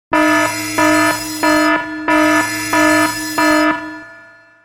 alarm enemy alert 01